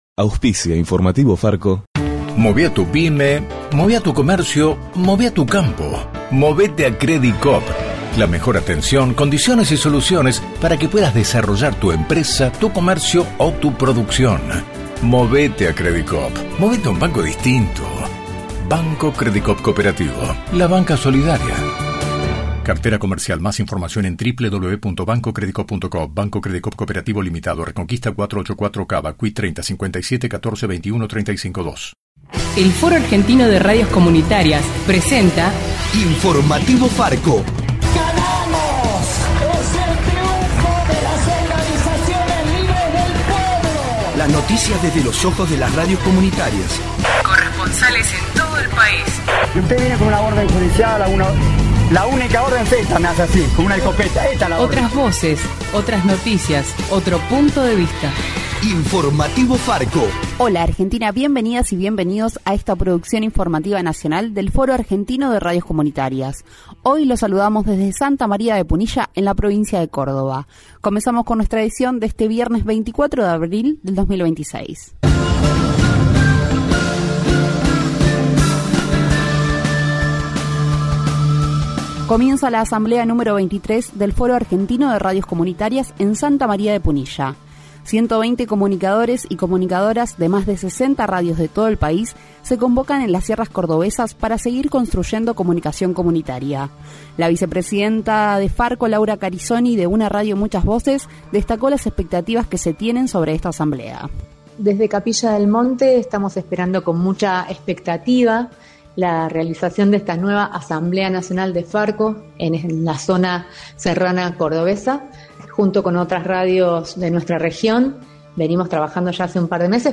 De la mano de FARCO (Foro Argentino de Radios Comunitarias) en Radio Atilra te acercamos el informativo más federal del país.